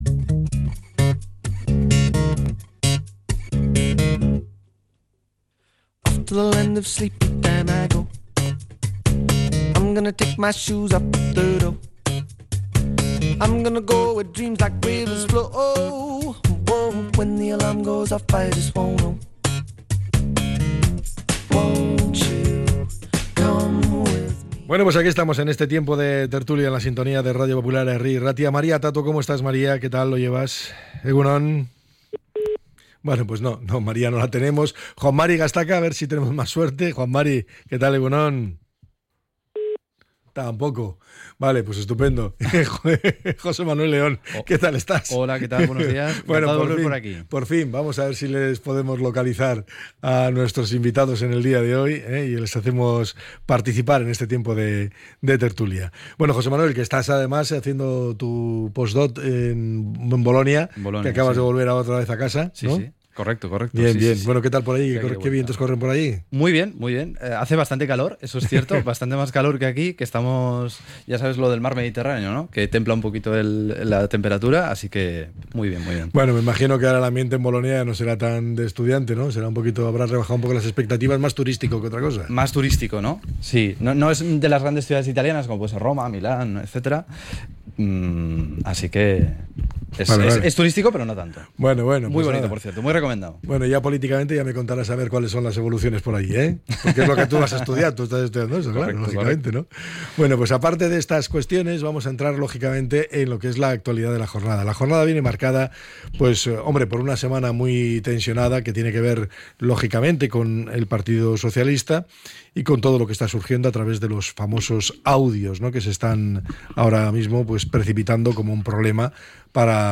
La tertulia 16-06-25.